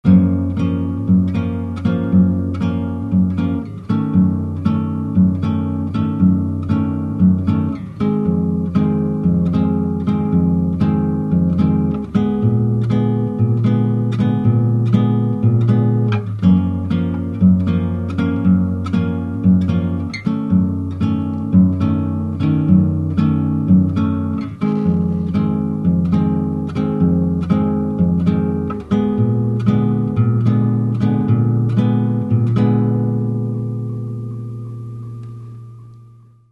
Vediamo ora il secondo esempio ritmico di bossanova. E' molto simile a quello mostrato nell'esempio precedente, ma questo ritmo è caratterizzato da un "anticipo" che introduce ogni battuta successiva.
Cliccando il pulsante qui sotto potrete prelevare il file musicale ed ascoltarne l'esecuzione (il giro armonico proposto viene ripetuto due volte).